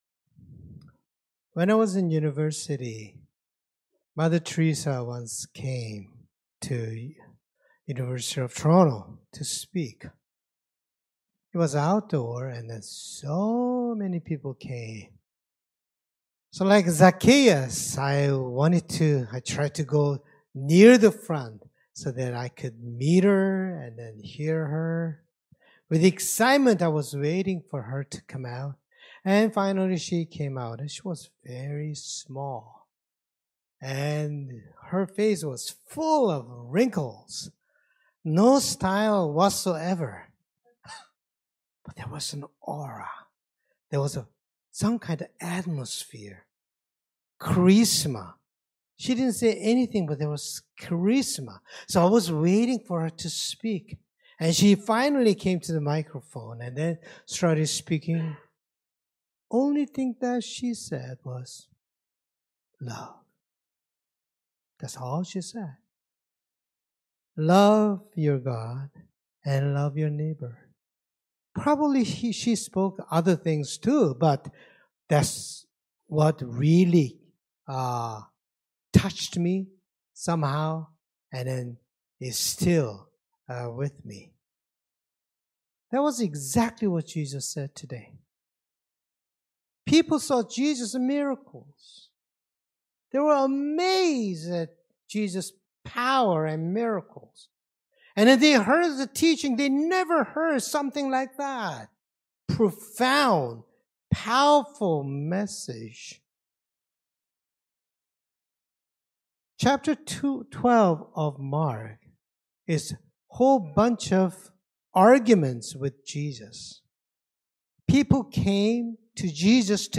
Scripture Passage Mark 12:28-34 Worship Video Worship Audio Sermon Script When I was in University, Mother Teresa once came to U of T to speak.